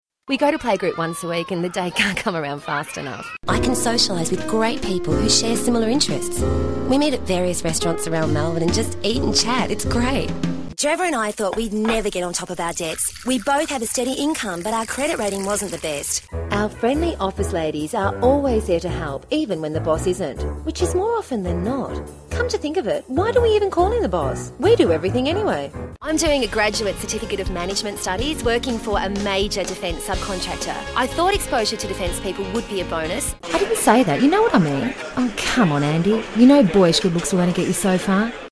General/Commercial Demo
Natural Retail
I am an Australian female voice over talent with over thirty years’ experience in the entertainment industry beginning as a children’s entertainer, puppeteer, and actor; happily immersing myself in accents and characters and learning early on the importance of emotionally connecting with my audience.
I have a great little home studio and use a Shure KSM microphone which means I am always available to provide a voice sample and meet tight deadlines for those fast turnaround jobs. I have a neutral English accent and can provide a great variety of voice styles: High energy retail reads ( I LOVE a good shop), smooth, mature, sincere, young and old.
03_natural.mp3